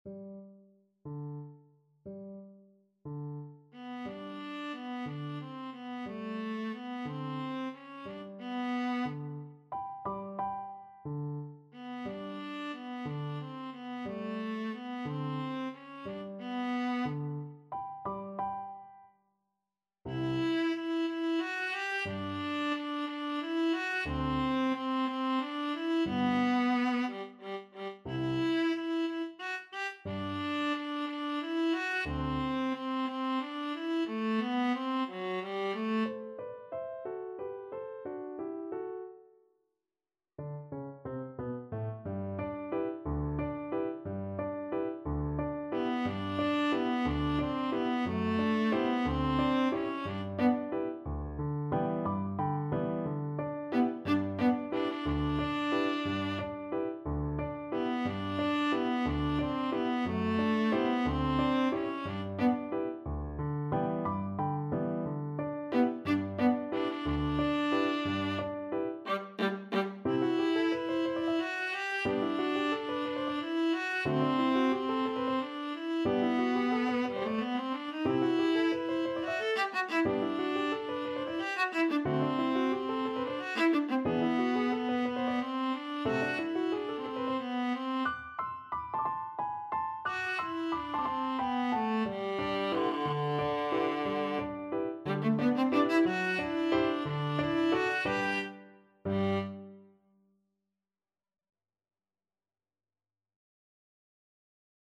6/8 (View more 6/8 Music)
Pochissimo pi mosso = 144 . =60
Classical (View more Classical Viola Music)